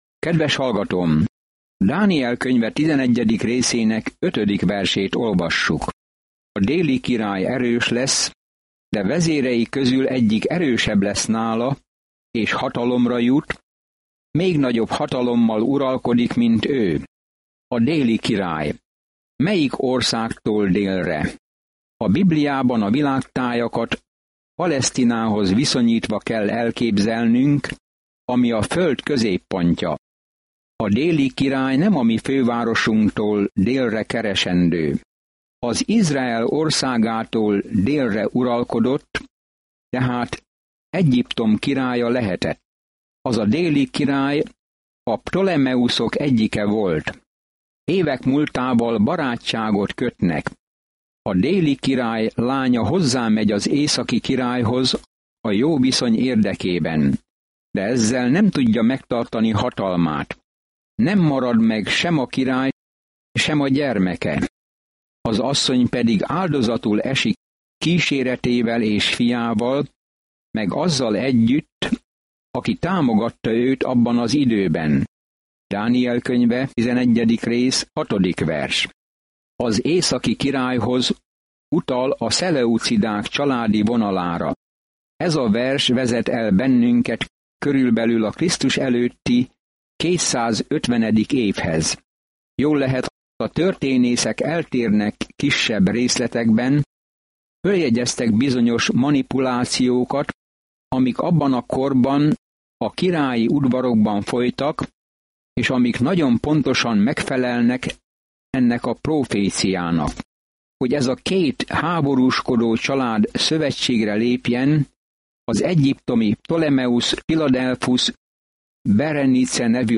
Szentírás Dániel 11:5-35 Nap 23 Olvasóterv elkezdése Nap 25 A tervről Dániel könyve egyszerre egy olyan ember életrajza, aki hitt Istenben, és egy prófétai látomás arról, hogy ki fogja végül uralni a világot. Napi utazás Dánielen keresztül, miközben hallgatod a hangos tanulmányt, és olvasol válogatott verseket Isten szavából.